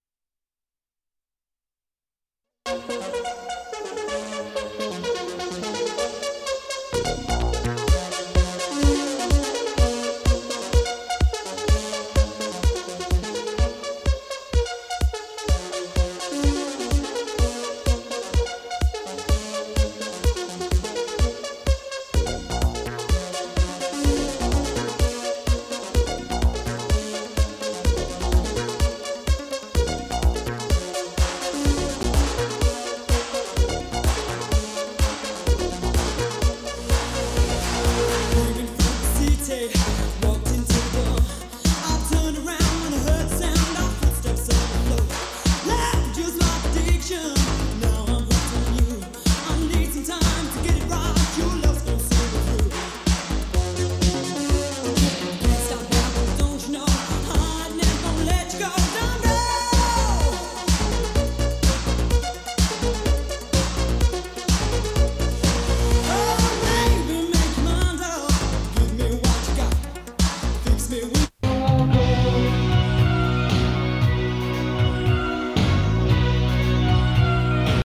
Here is a test recording made and played back on the TC-WR590: